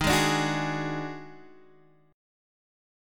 D# Minor Major 7th Double Flat 5th